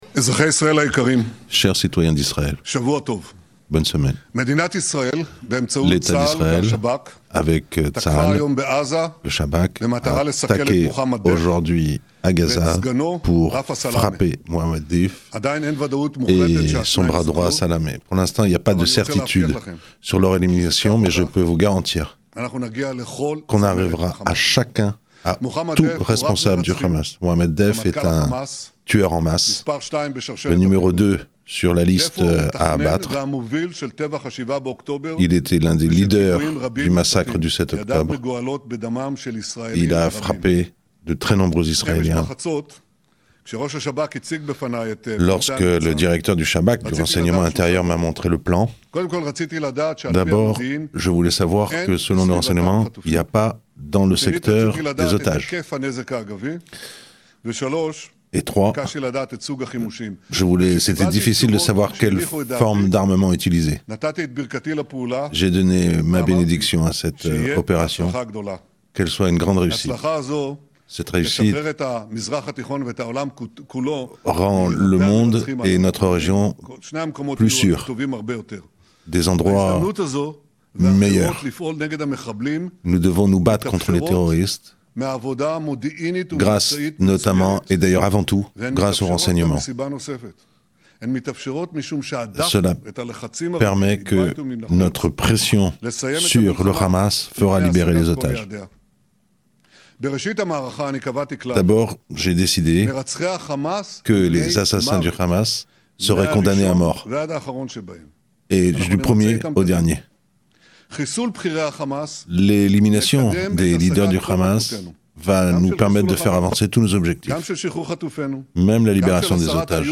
Elimination de Mohamed Deif : Ecoutez le message de Benjamin Netanyahou traduit en français